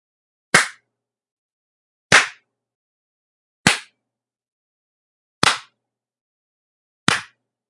掴脸
描述：三个温柔的振奋人心的耳光打在脸上。
Tag: 拍击